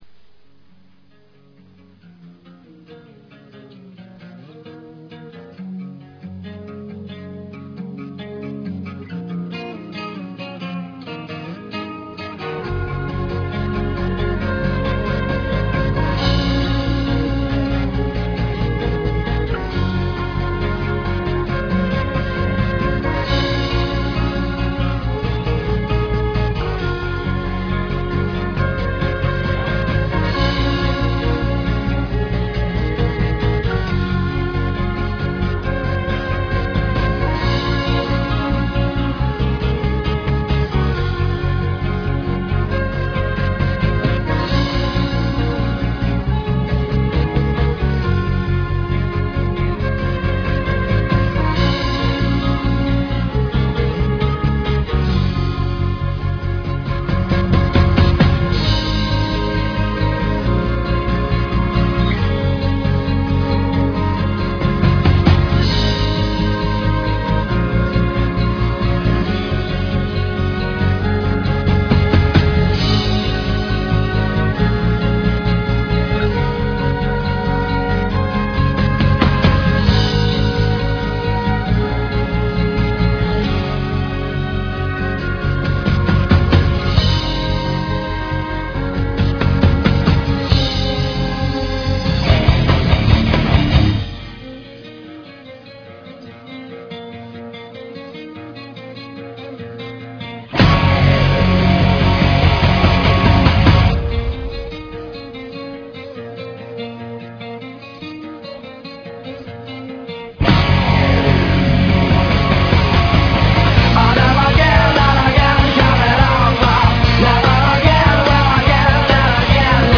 Mixing traditional style with effects all over it.